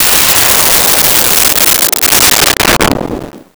Missle 08
Missle 08.wav